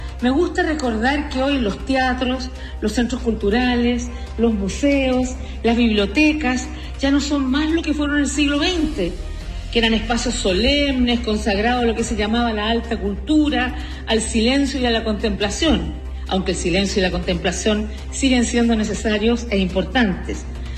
Con la presentación de la obra Violeta Parra Sinfónico el miércoles se realizó la inauguración del nuevo Teatro Regional del Bío Bío, que se enmarca dentro de la última visita de Michelle Bachelet como presidenta a Concepción.